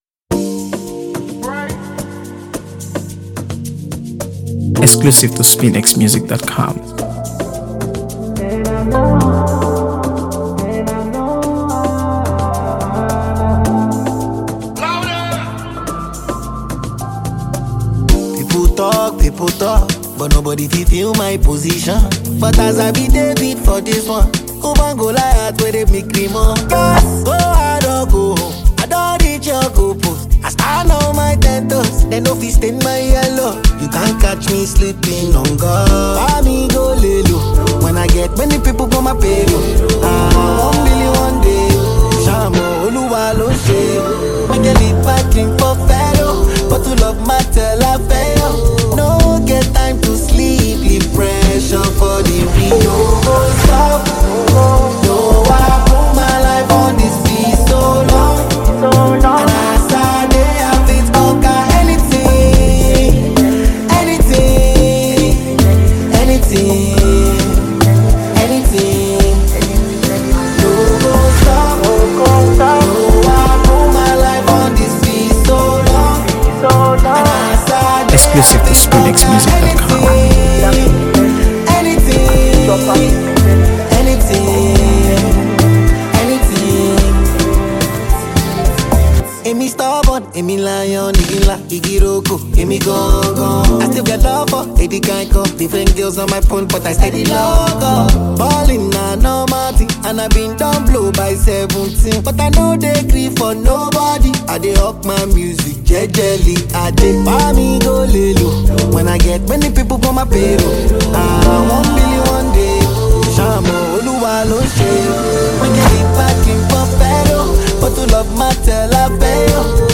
AfroBeats | AfroBeats songs
Blending his signature charisma with a fresh, vibrant sound
catchy melodies, heartfelt lyrics, and undeniable energy.
love song